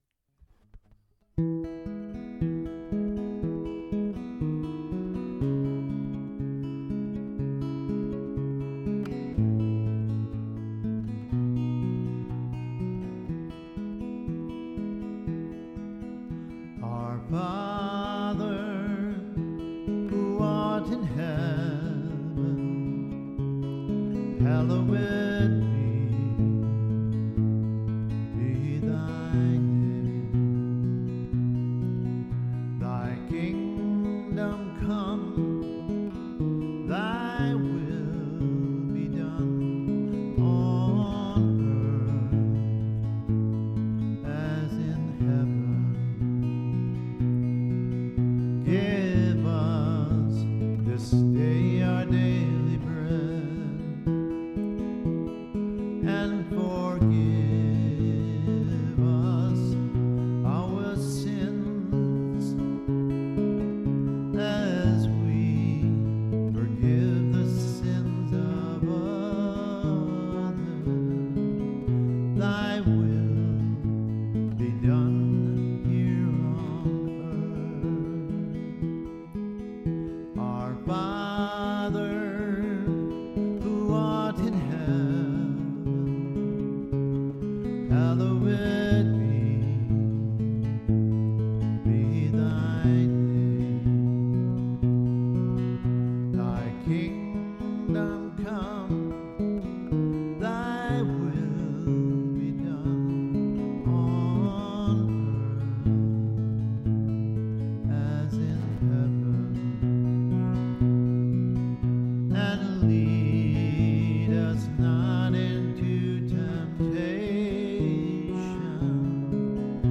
When I sing it, it is a something coming out of my spirit.